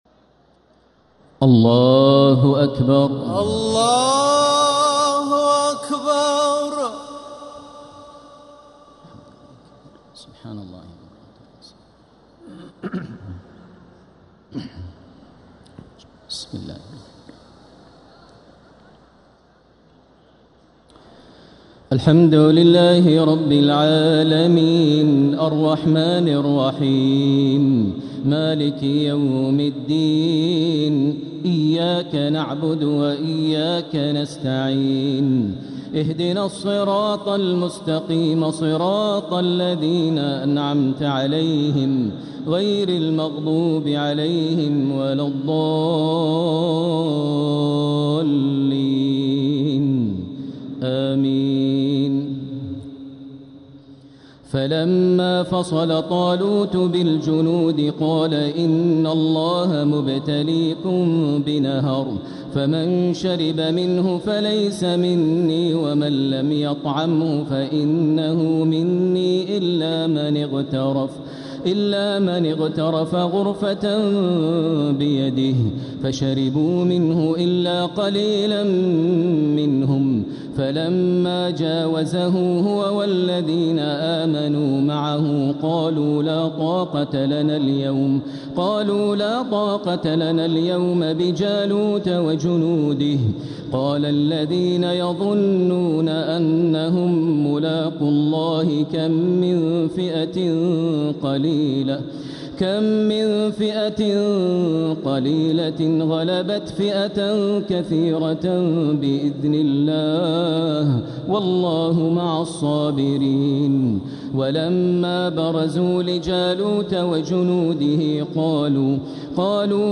تراويح ليلة 3 رمضان 1447هـ من سورة البقرة {249-271} Taraweeh 3rd night Ramadan 1447H > تراويح الحرم المكي عام 1447 🕋 > التراويح - تلاوات الحرمين